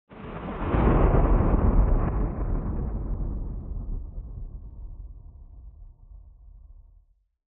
دانلود آهنگ رعدو برق 7 از افکت صوتی طبیعت و محیط
دانلود صدای رعدو برق 7 از ساعد نیوز با لینک مستقیم و کیفیت بالا
جلوه های صوتی